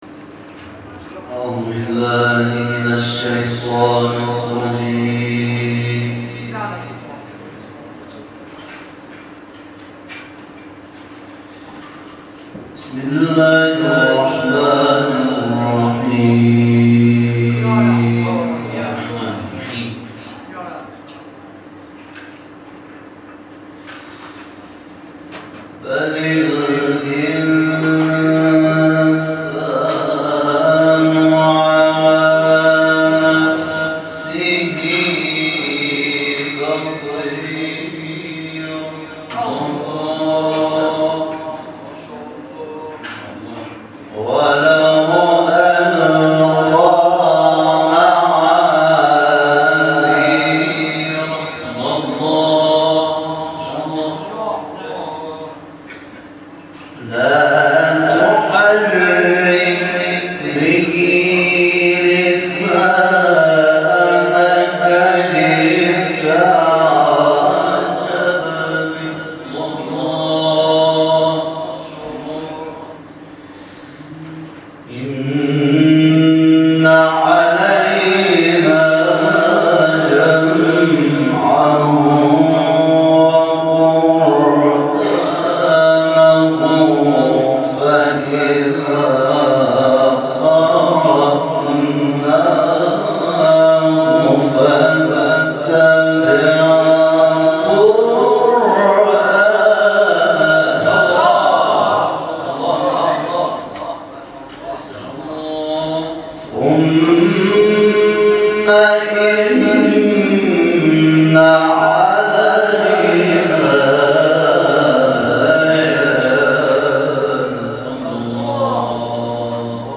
تلاوت قاری افغانستان از سوره القیامة